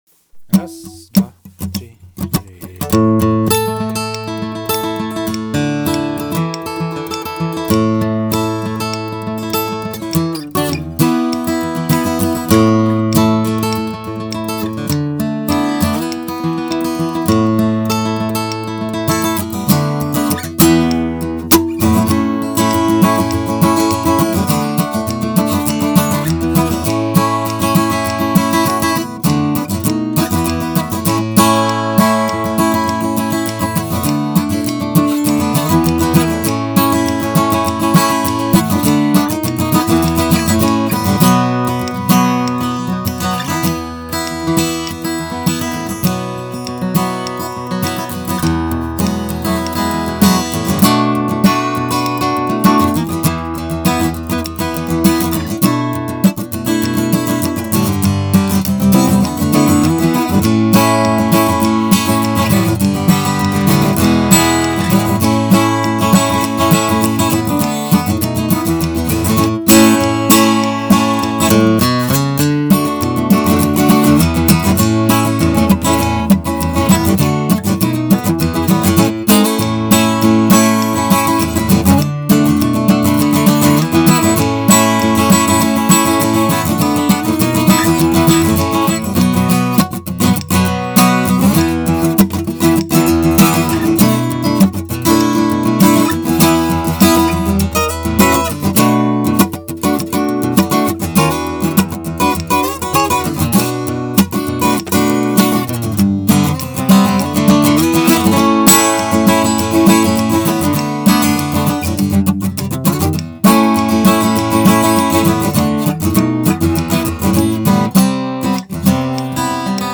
v A Dur🎵 zdroj - video